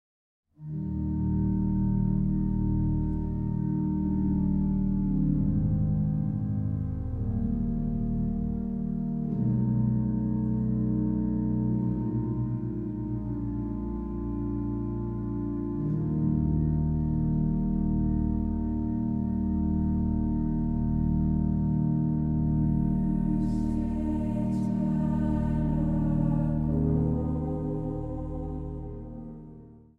Instrumentaal
Zang